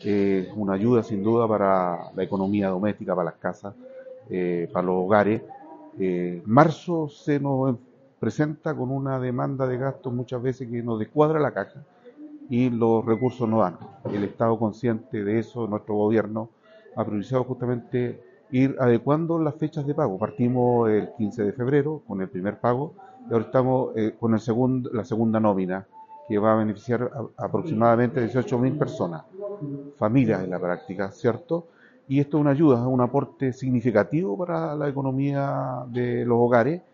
Mientras que el Seremi de Desarrollo Social y Familia, comentó que
Seremi-de-Desarrollo-Social.mp3